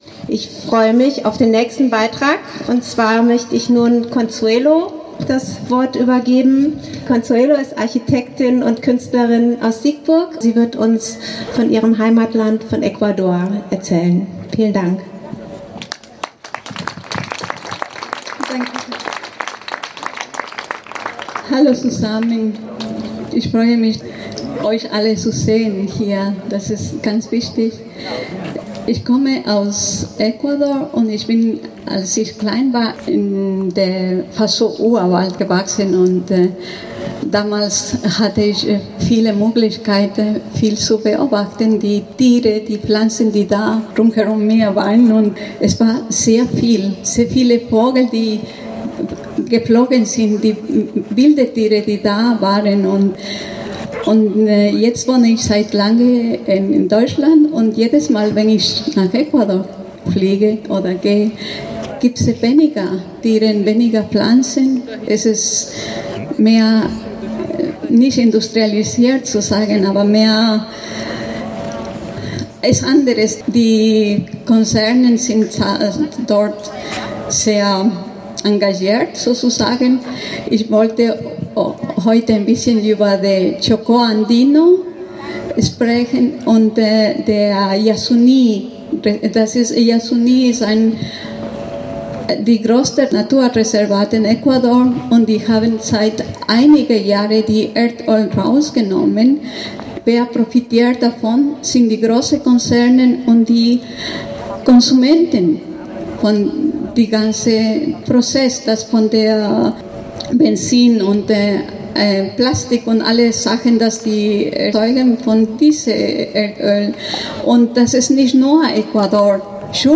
Abschlusskundgebung